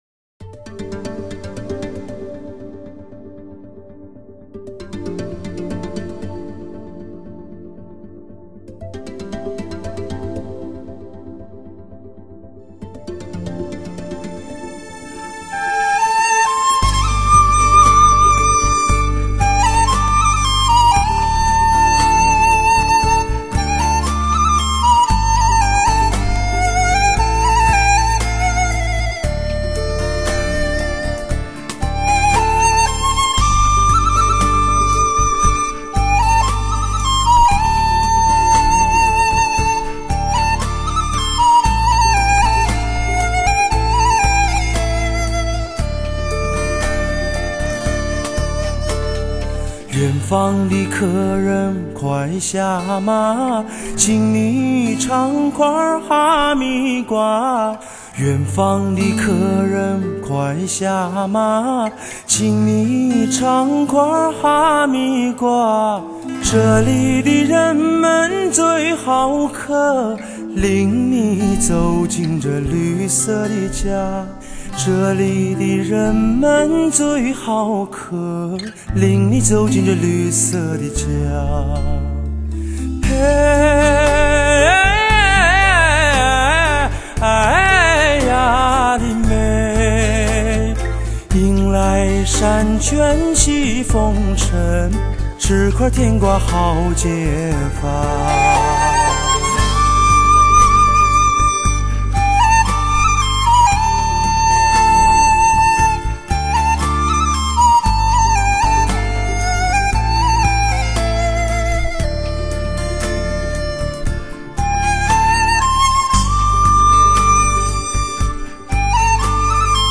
★真实细腻极具层次的优质动态声场排山倒海且绝不耳不止
低品质62k/wma